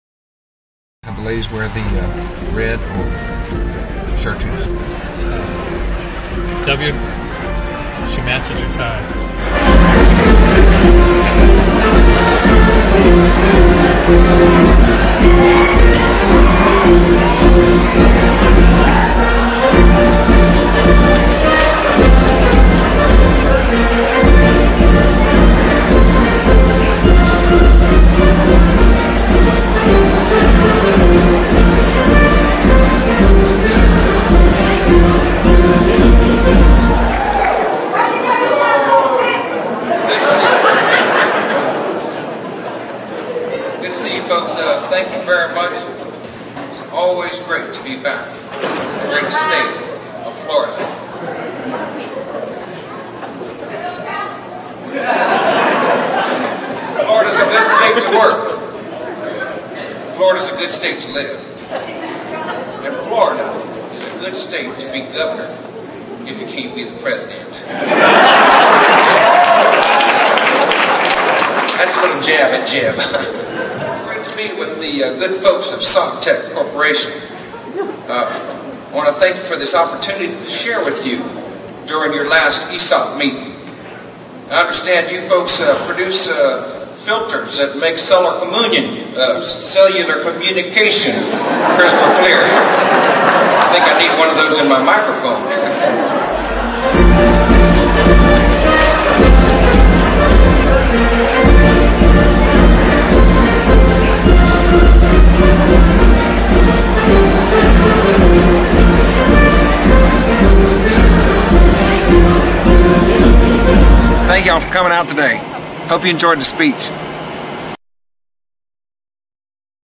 George W. Bush impersonator